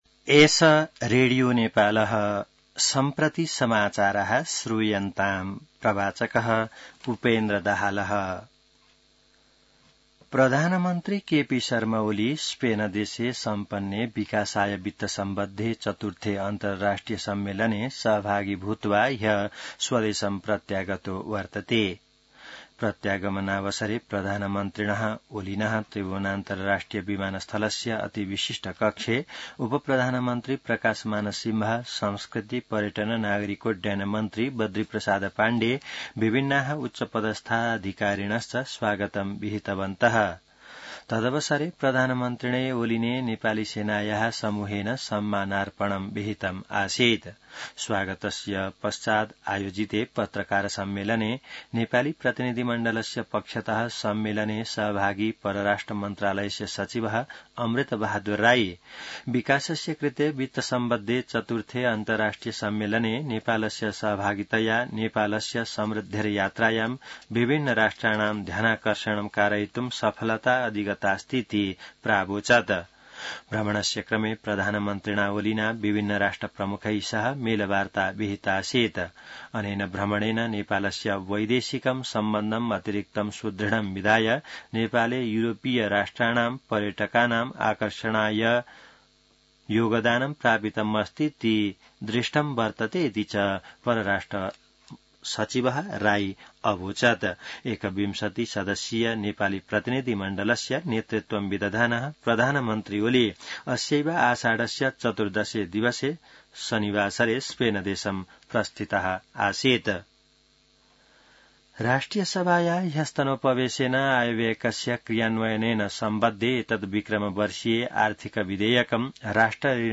संस्कृत समाचार : २१ असार , २०८२